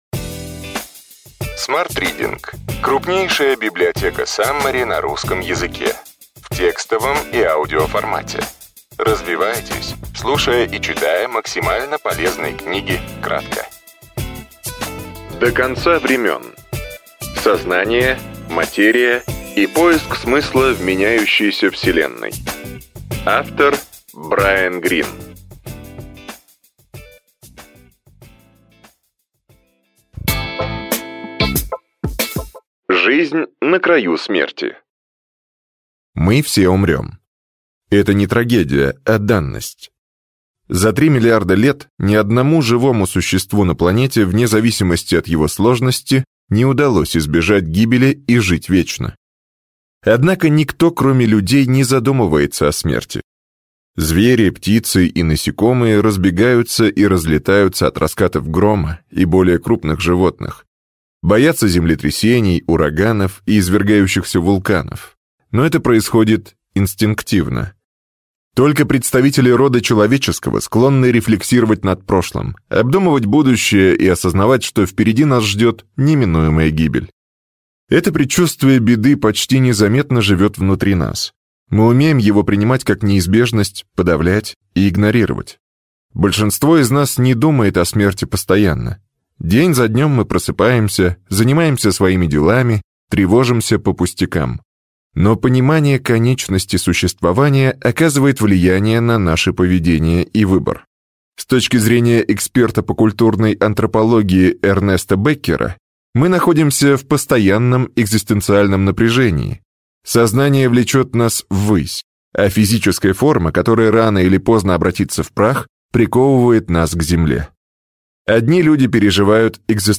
Аудиокнига Ключевые идеи книги: До конца времен. Сознание, материя и поиск смысла в меняющейся Вселенной. Брайан Грин | Библиотека аудиокниг